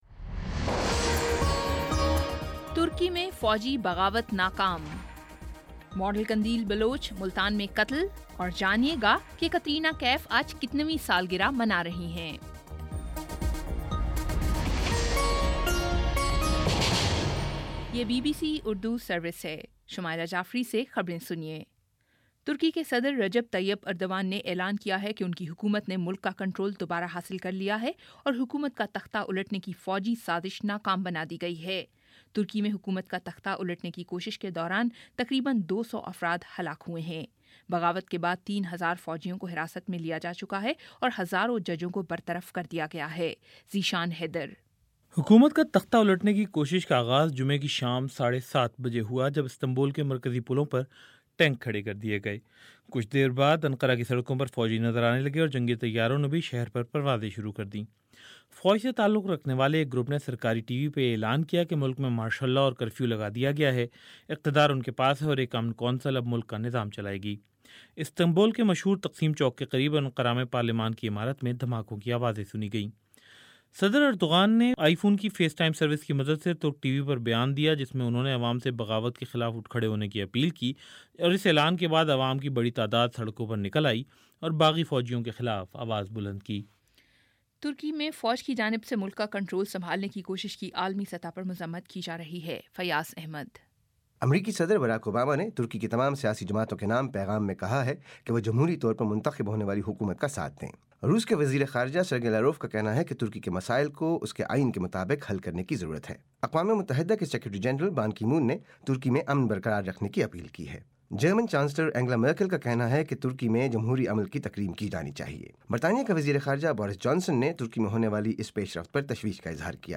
جولائی 16 : شام چھ بجے کا نیوز بُلیٹن